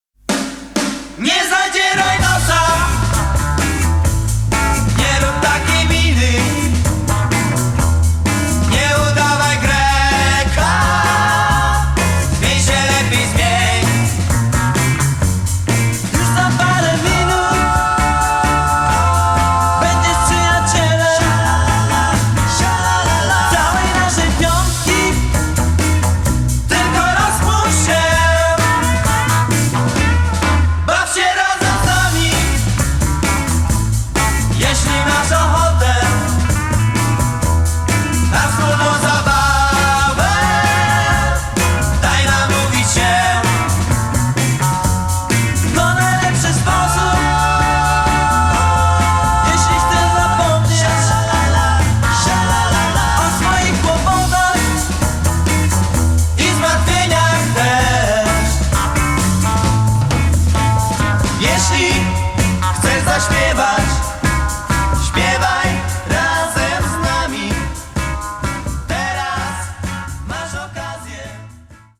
VOC GUITAR KEYB BASS DRUMS TEKST
Był autorem również dynamicznych, rock’n’rollowych przebojów